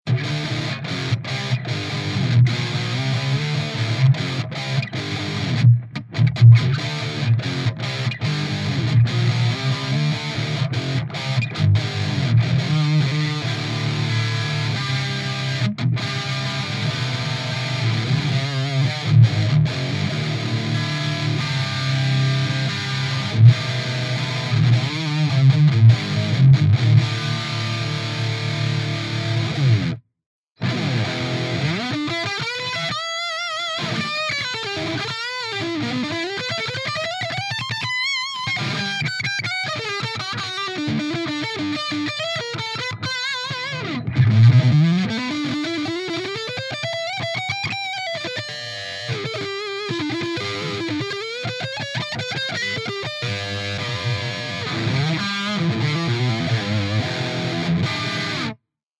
Poslal mi ukazku z vystupu slave + nahravku mikrofonom od bedne pri izbovej hlasitosti.
Prvy je cisty linkovy signal, druhe je mikrofon od bedne. ostatne su impulzy. podla nazvov tych impulzov by malo ist o JCM2000, Plexi a Mesa Boogie nejake: